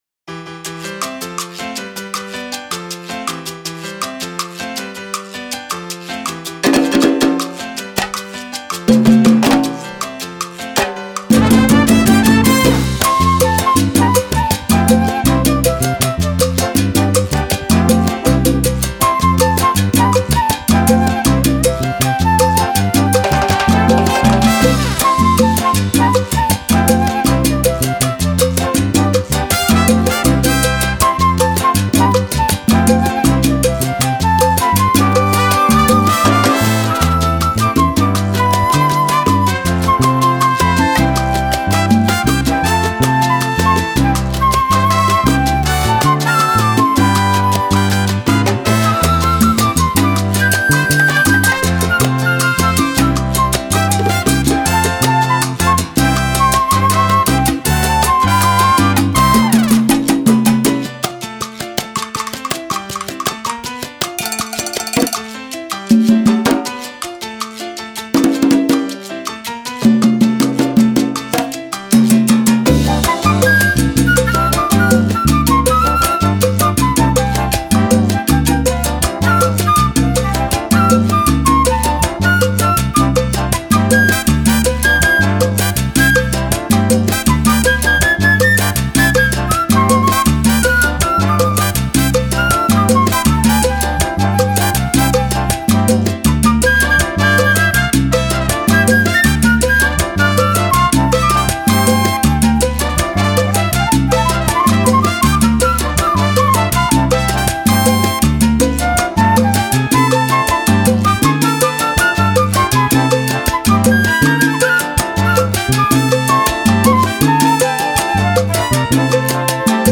Latin Dance Collection